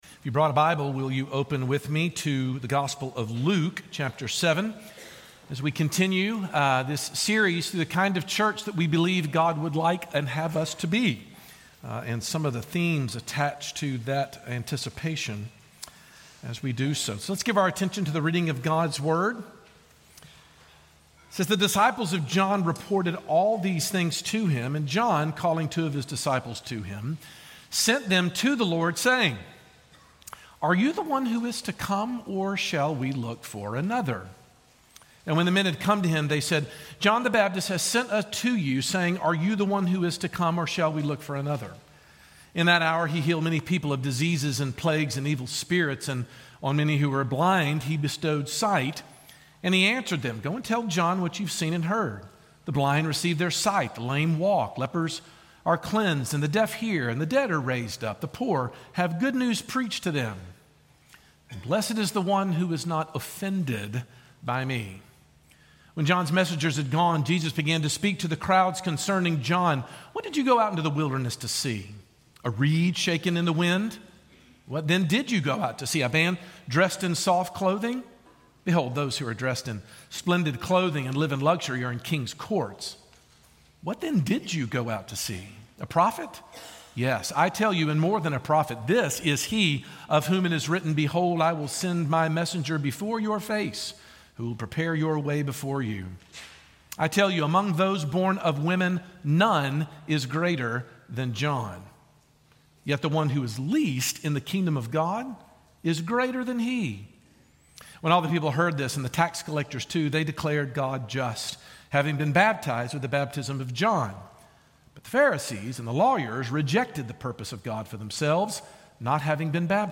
Sermons in this Series